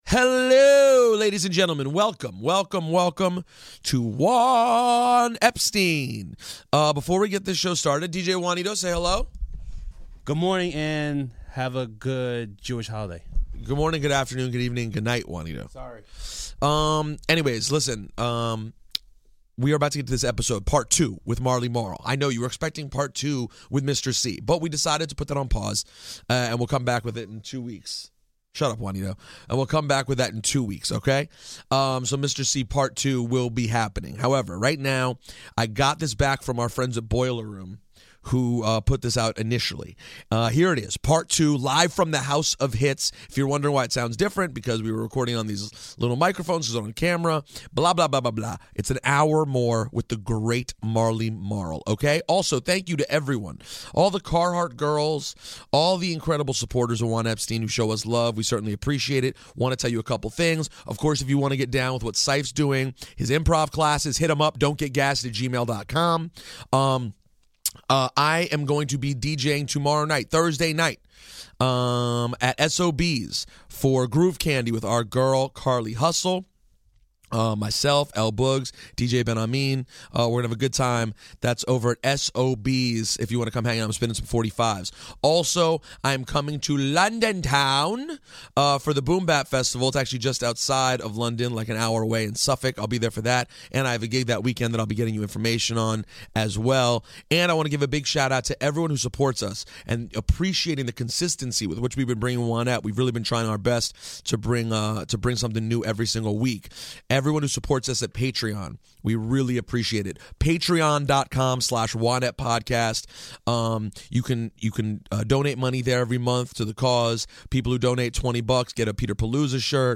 Live from the House of Hits and this time Marley is playing us rarities discussed on Part 1!